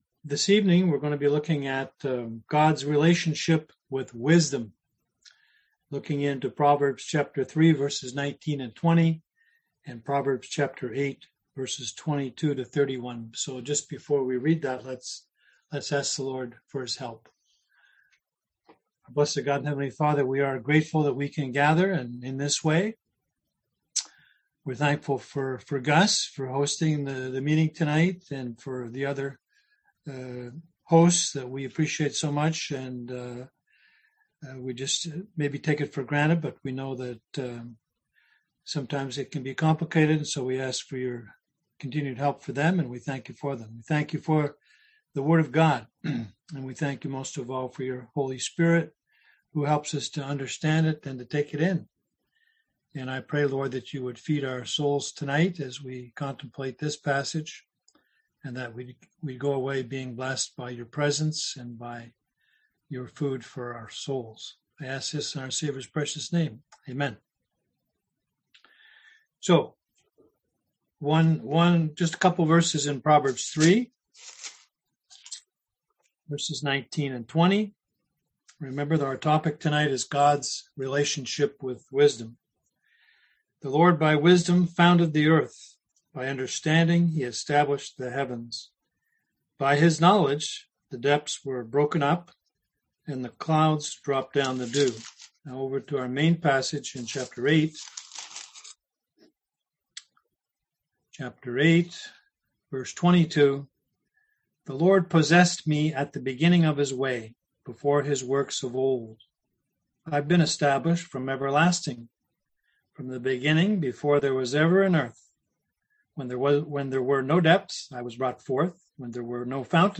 Series: Wisdom Passage: Proverbs 3:19-20; Proverbs 8:22-31 Service Type: Seminar